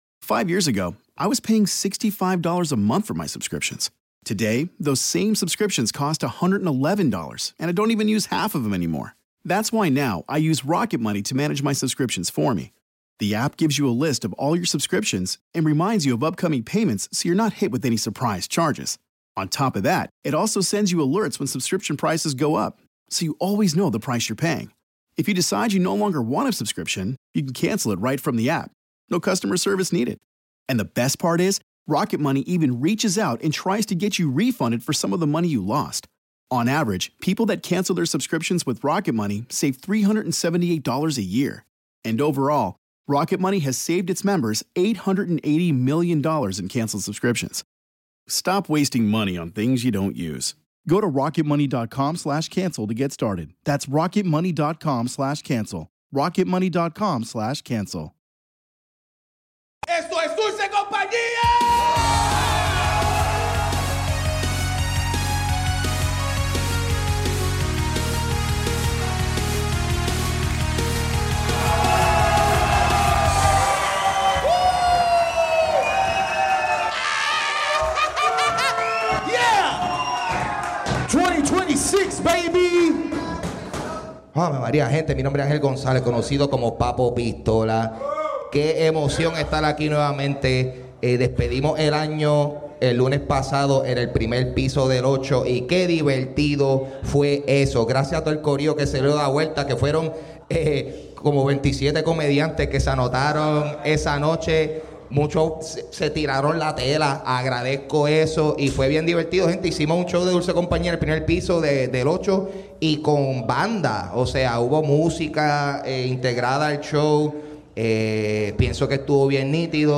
Celebramos víspera de Reyes con nuestro primer open mic del año.